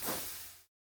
Minecraft Version Minecraft Version snapshot Latest Release | Latest Snapshot snapshot / assets / minecraft / sounds / block / candle / extinguish1.ogg Compare With Compare With Latest Release | Latest Snapshot
extinguish1.ogg